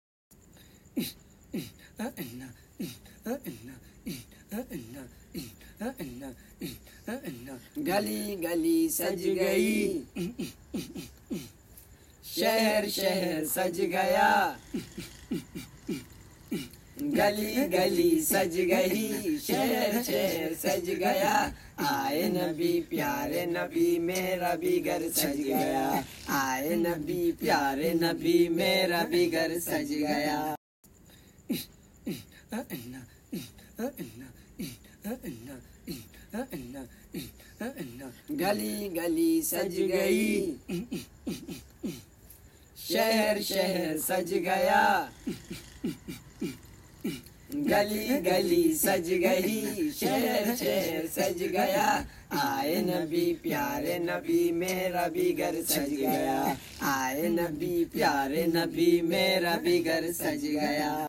12 rabiulawwal naat sharif part